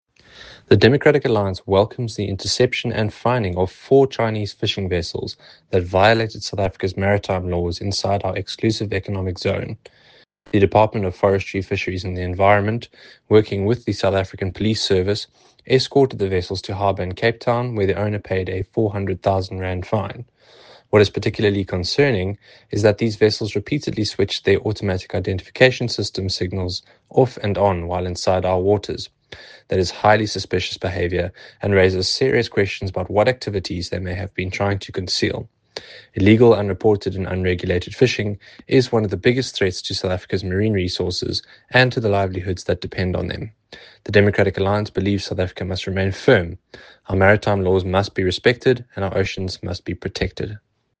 Issued by Andrew de Blocq MP – DA Spokesperson for Forestry, Fisheries and the Environment
Afrikaans soundbites by Andrew de Blocq MP.
Andrew-de-Blocq-MP_English_-Chinese-fishing-vessels.mp3